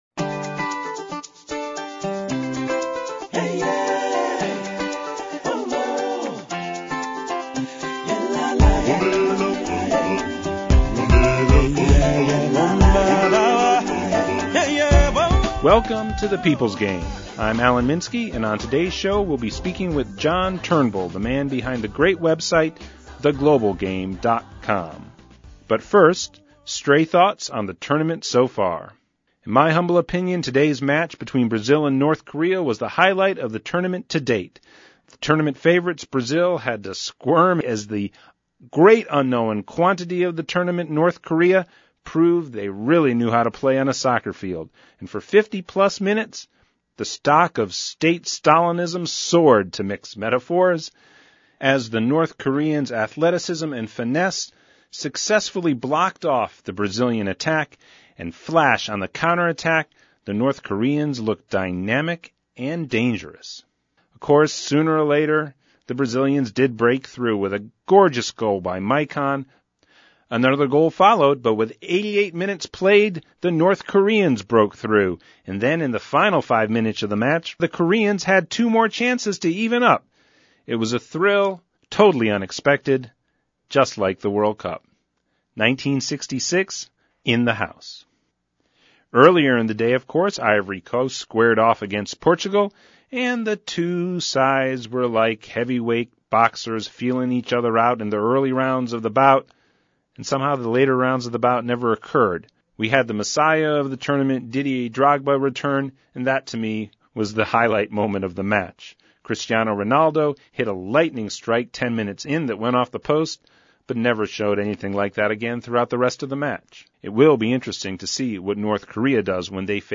Category Radio Show